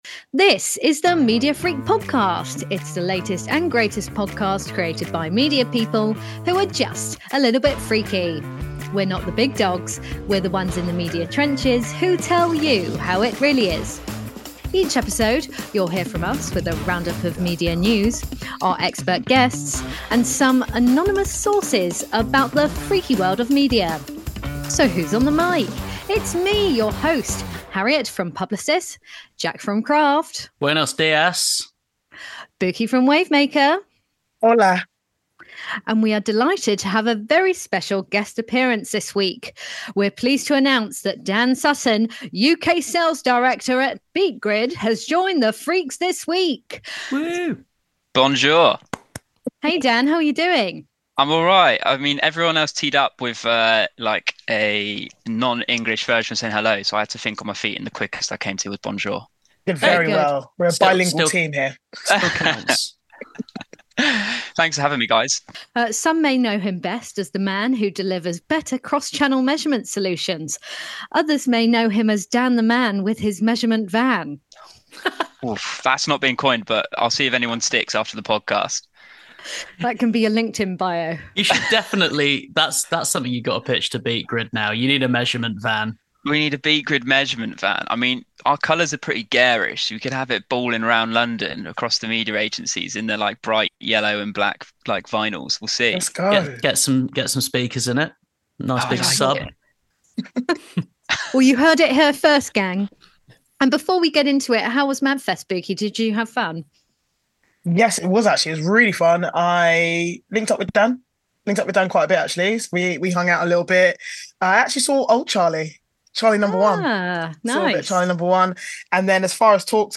Welcome to the marketing and advertising podcast presented by a team from across the industry.